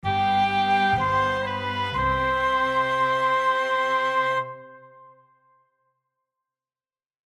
Plays short end of the track